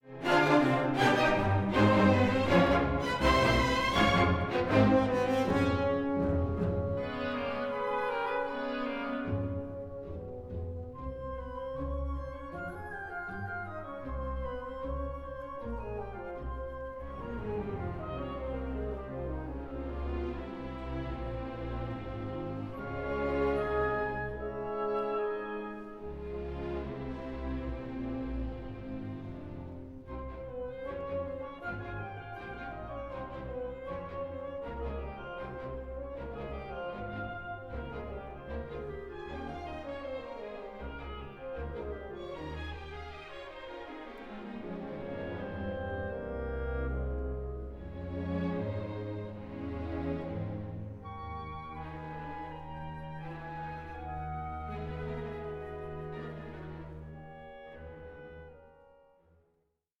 Symphony No. 2 in D Major, Op. 73